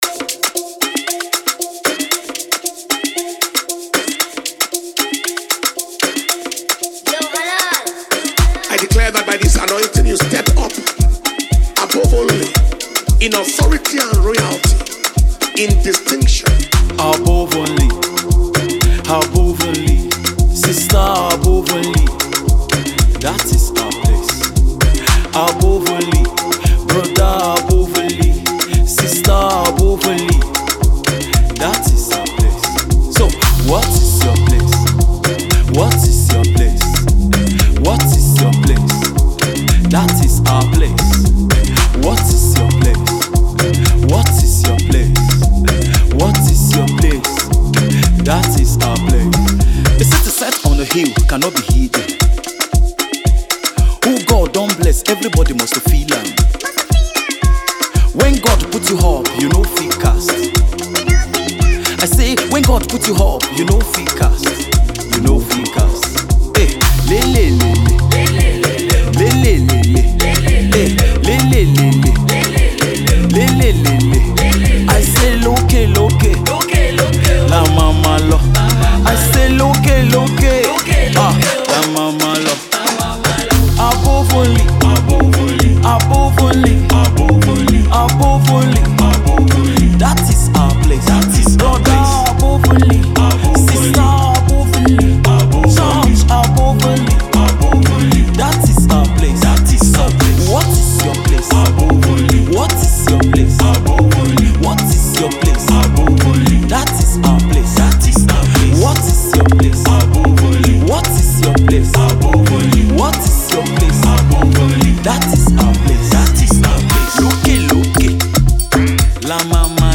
Nigerian gospel singer